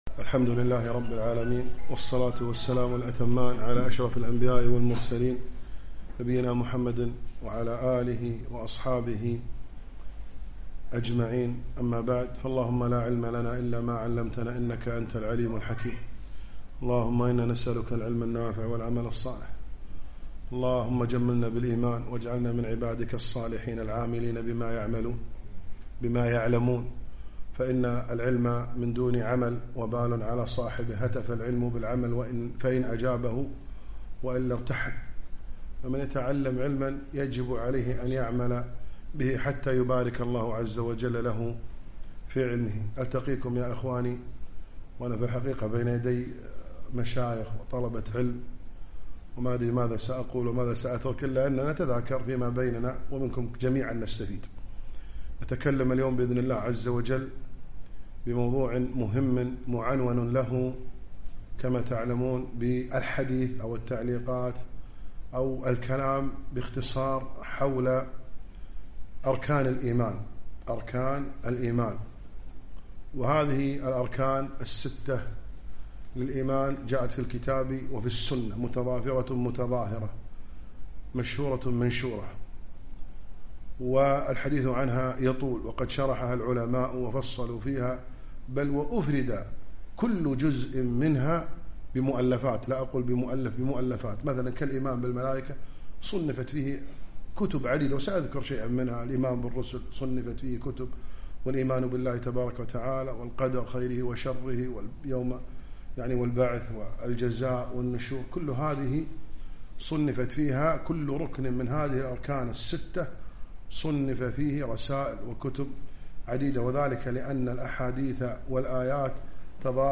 محاضرة - أركان الإيمان